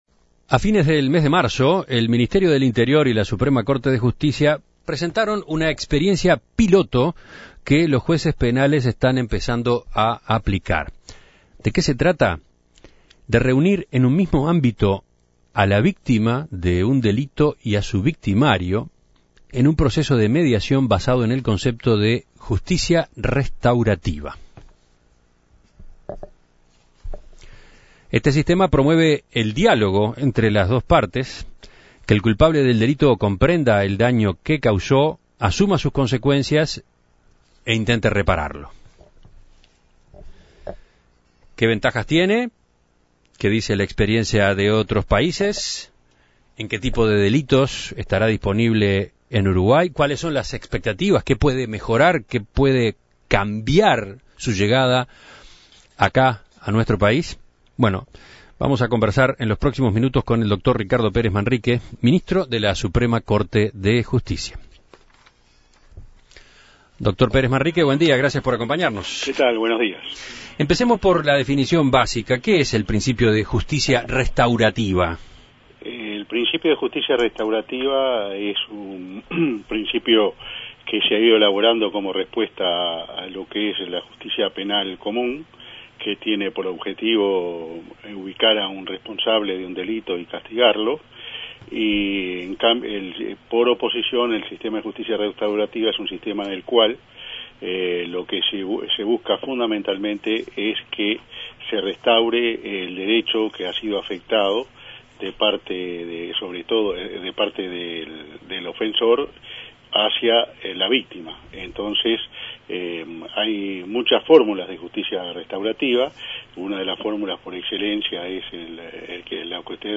Para conocer la importancia de este sistema, En Perspectiva dialogó con Ricardo Pérez Manrique, ministro de la SCJ.